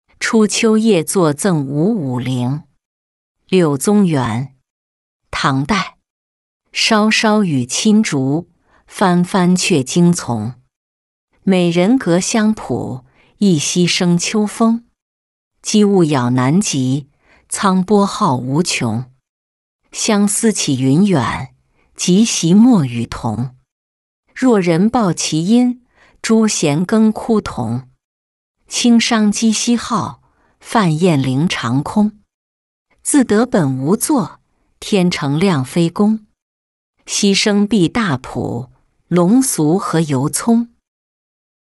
初秋夜坐赠吴武陵-音频朗读